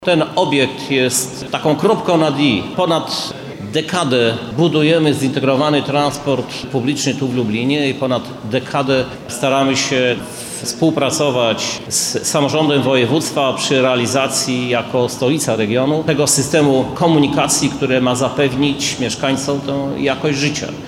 Krzysztof Żuk -mówi Krzysztof Żuk, Prezydent Miasta Lublin